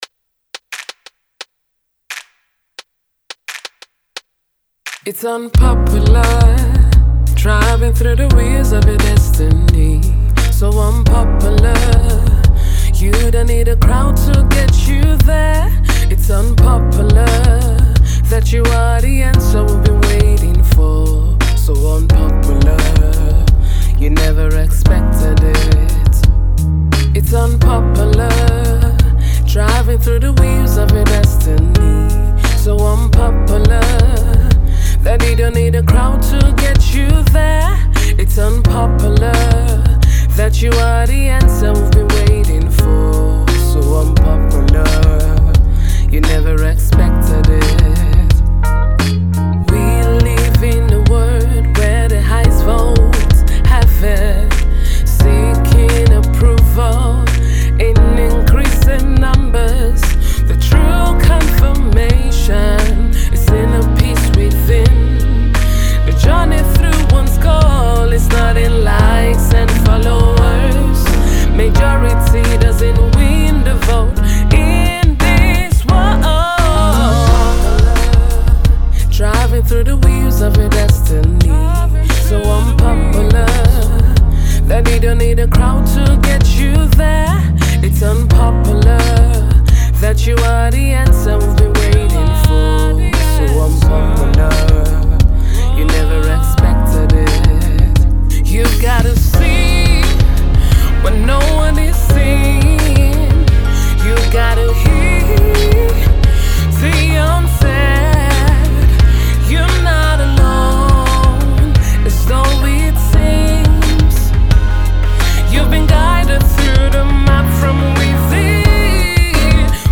singer songwriter